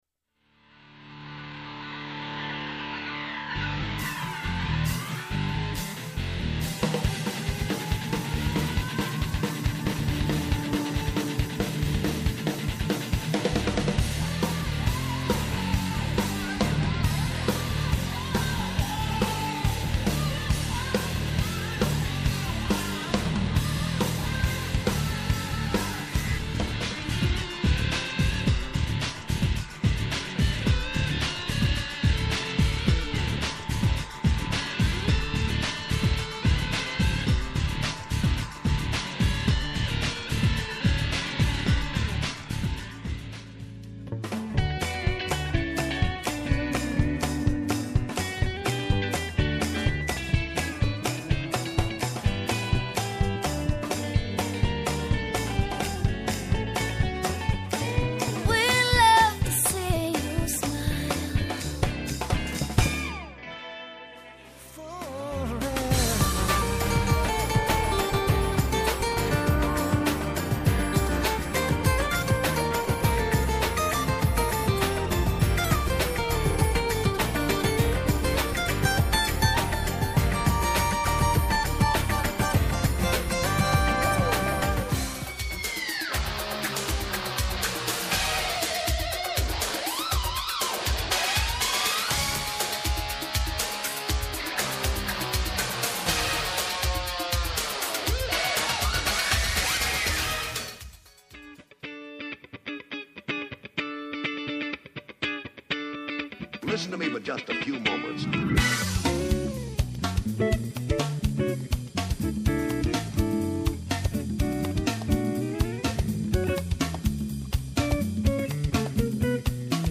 Guitar Samples
Studio Demo #1